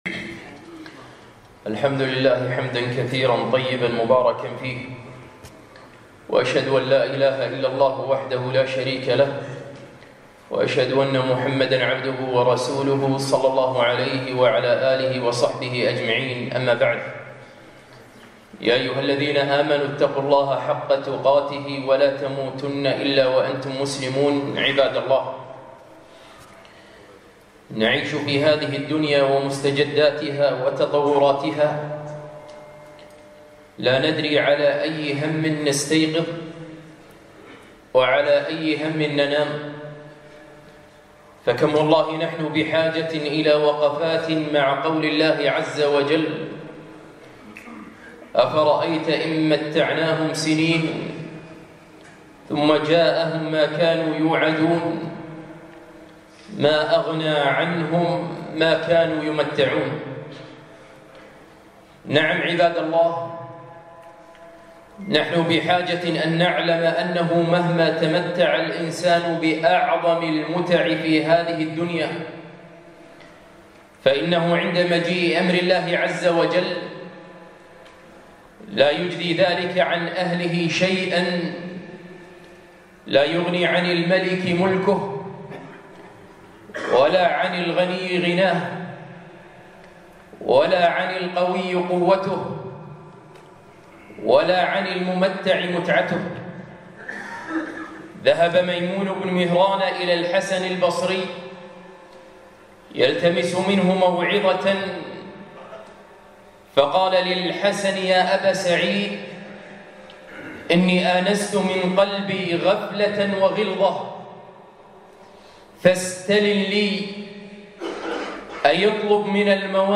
خطبة - أفرأيت إن متعناهم سنين؟!